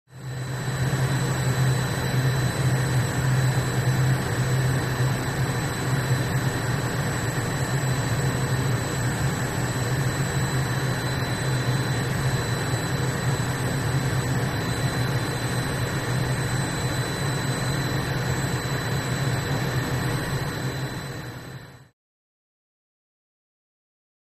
Lear Jet Engine Idle From Cockpit